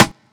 SFSF_RIM.wav